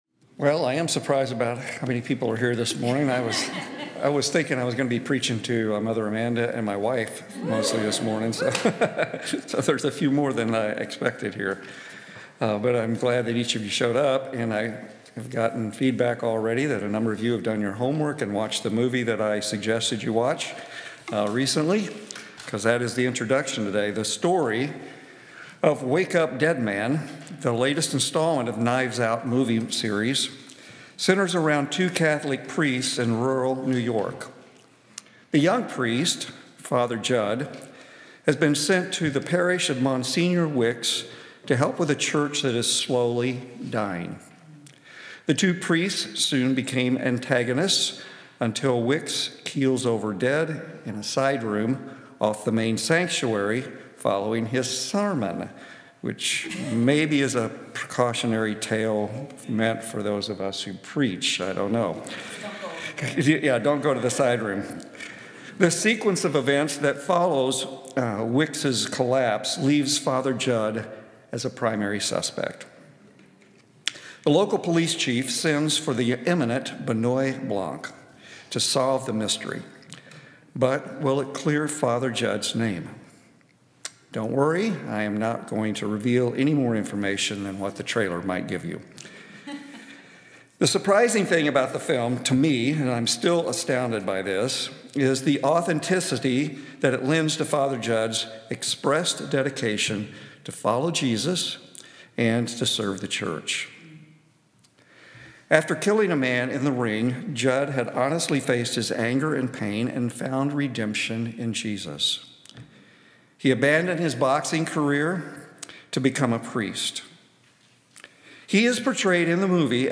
COR Sermon – January 25, 2026
Sermon -